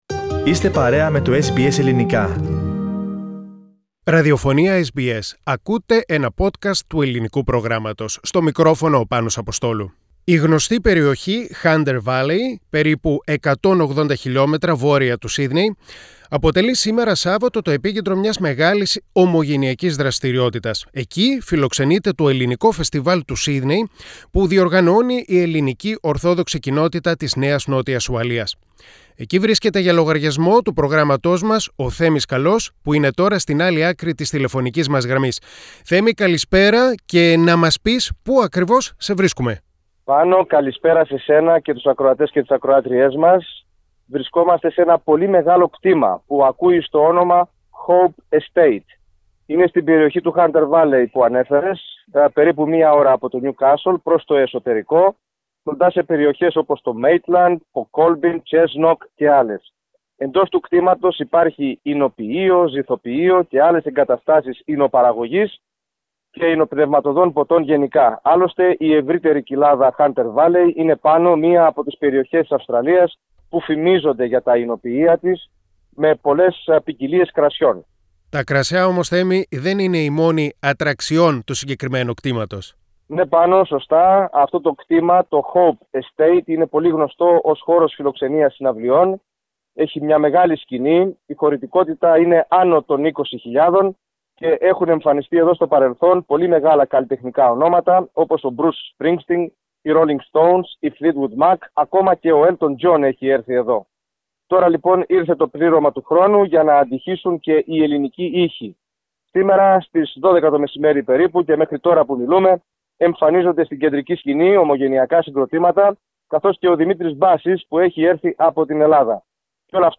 Πατήστε Play στην κεντρική φωτογραφία για να ακούσετε ρεπορτάζ της SBS Greek για το Ελληνικό Φεστιβάλ του Σύδνεϋ στο Hunter Valley της Νέας Νότιας Ουαλίας Share